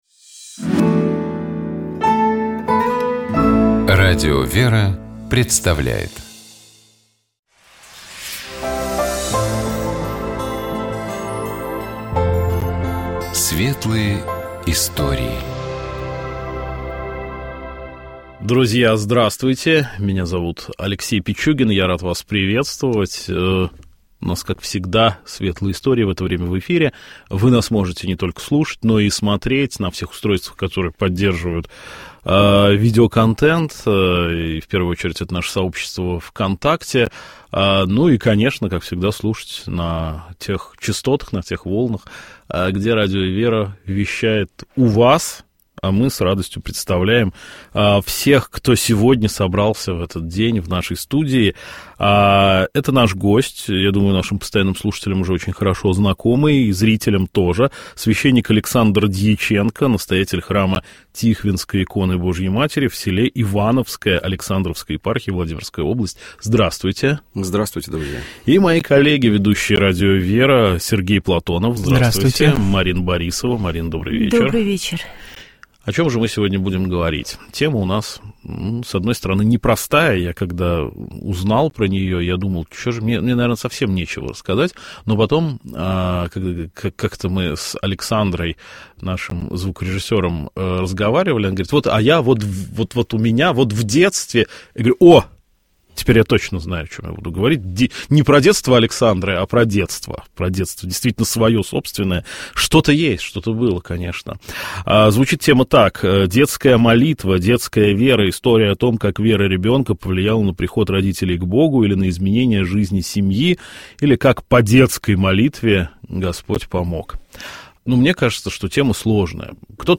Беседа из цикла про образование, который Радио ВЕРА организует совместно с образовательным проектом «Клевер Лаборатория», которая объединяет